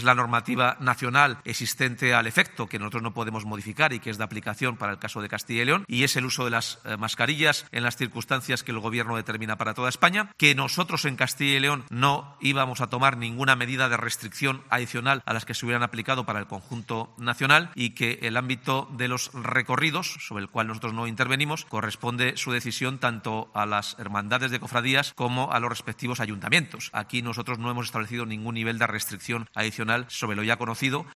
el portavoz de la Junta de Castilla y León, Carlos Fernández sobre Semana Santa